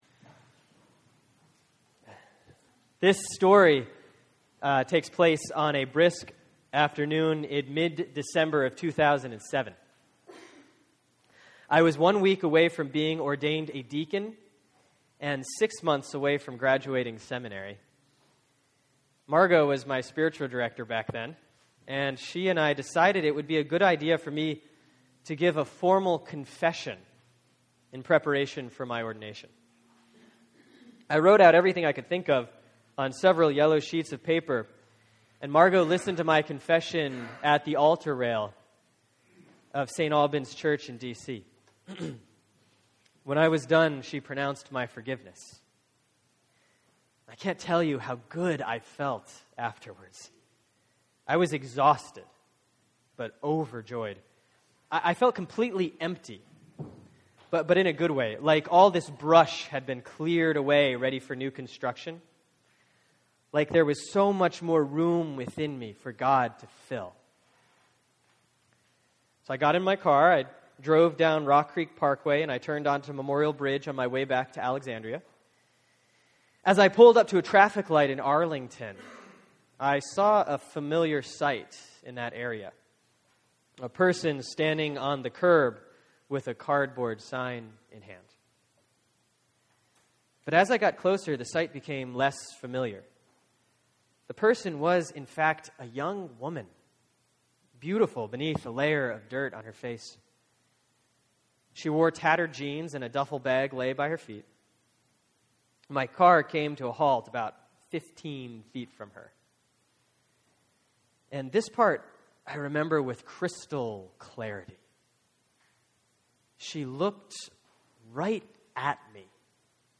Sermon for Sunday, September 29, 2013 || Proper 21C || Luke 16:19-31; 1 Timothy 6:6-19)